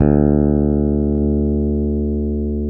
Index of /90_sSampleCDs/Roland L-CD701/BS _E.Bass 5/BS _Dark Basses